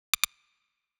chat-message-received.wav